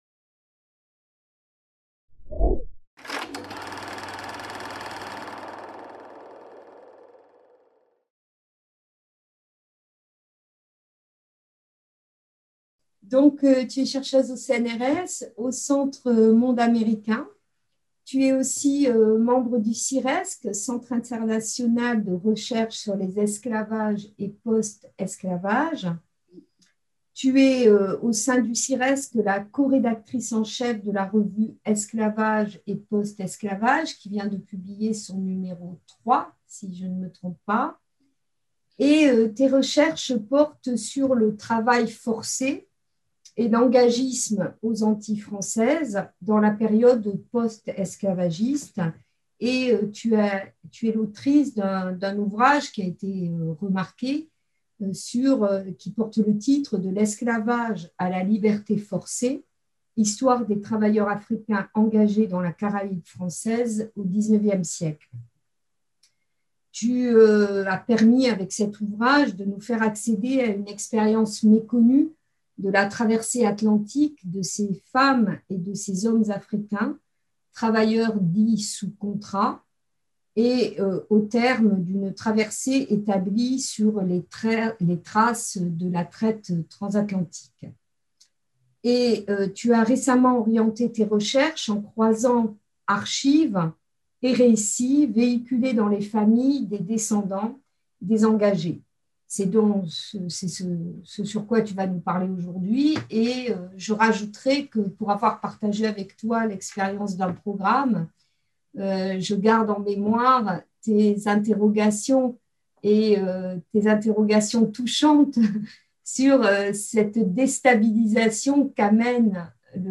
CNRS Discussion avec les intervenants de la matinée